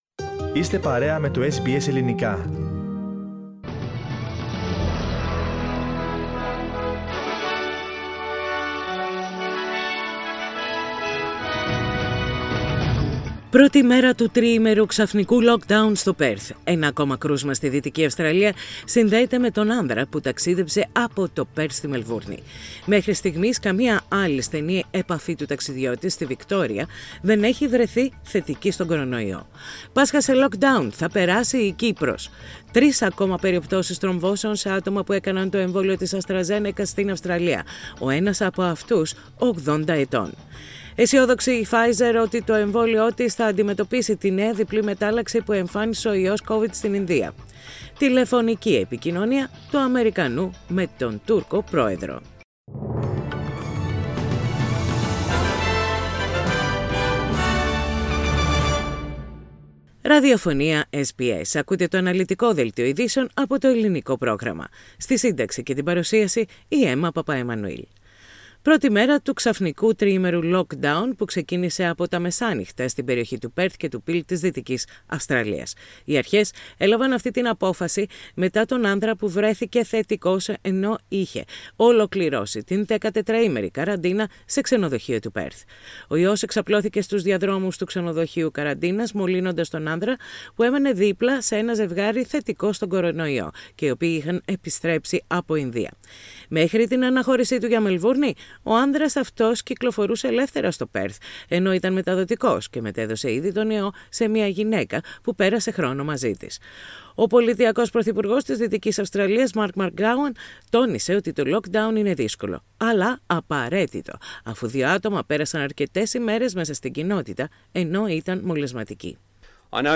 Ειδήσεις στα Ελληνικά - Σάββατο 24.4.21